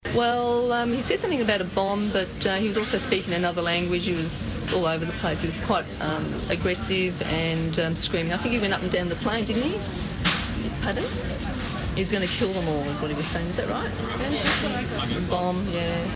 Plane